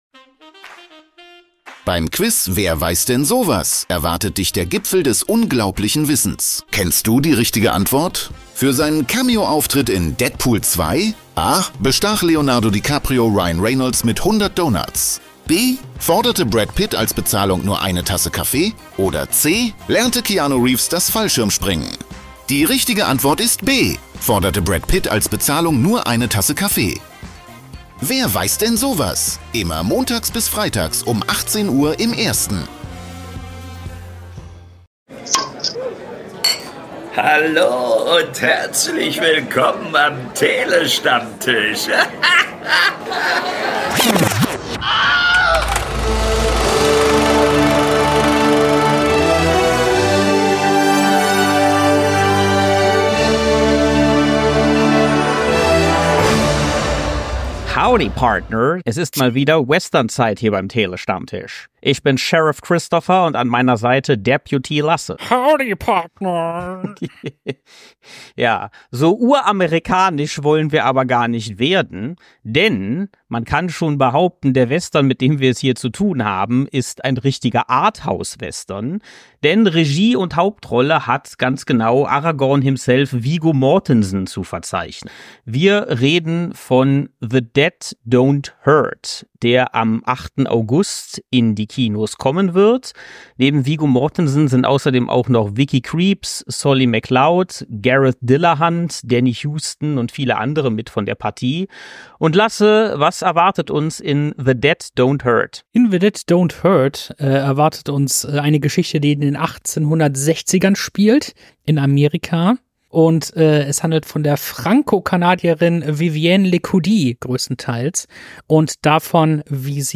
Wir liefern euch launige und knackige Filmkritiken, Analysen und Talks über Kino- und Streamingfilme und -serien - immer aktuell, informativ und mit der nötigen Prise Humor. Website | Youtube | PayPal | BuyMeACoffee Großer Dank und Gruß für das Einsprechen unseres Intros geht raus an Engelbert von Nordhausen - besser bekannt als die deutsche Synchronstimme Samuel L. Jackson!